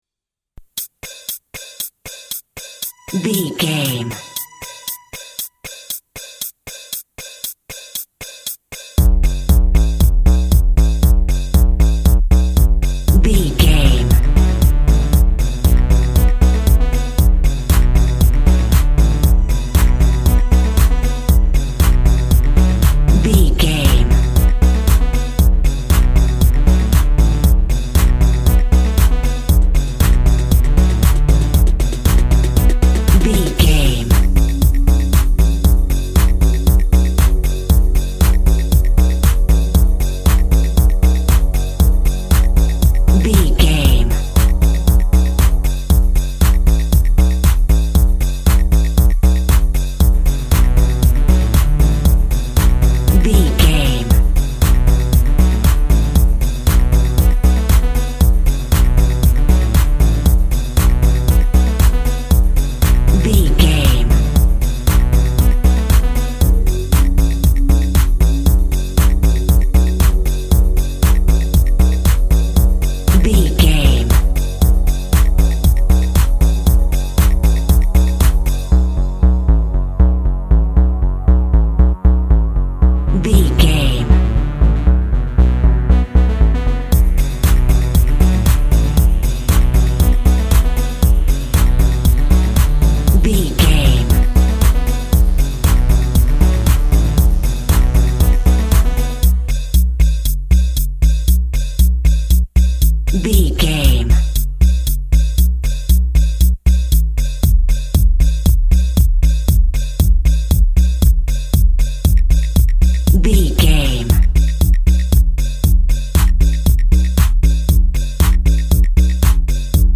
Mid Tempo House Music.
Aeolian/Minor
dark
futuristic
groovy
drum machine
synthesiser
energetic
forceful
synth lead
synth bass
electronic drums
Synth Pads